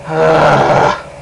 Growl Sound Effect
Download a high-quality growl sound effect.
growl-2.mp3